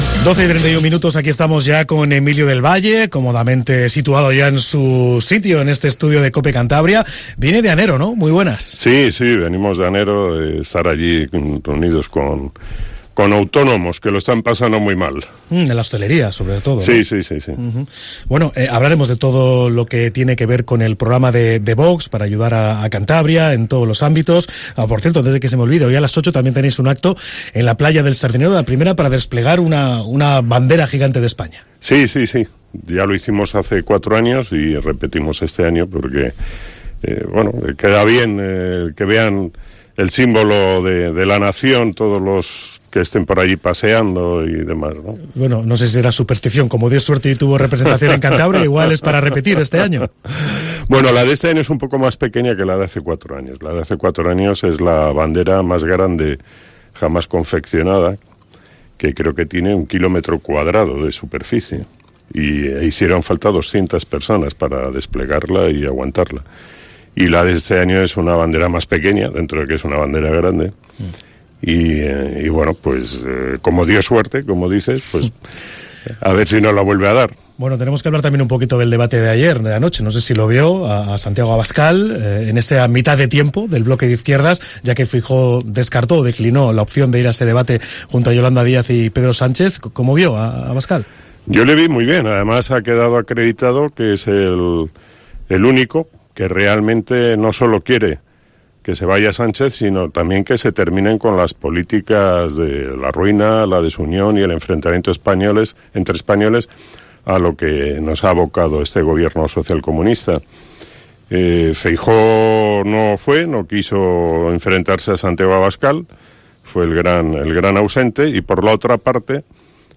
Escucha a Emilio del Valle, candidato nº1 al Congreso por parte de Vox en Cantabria, en la entrevista en Cope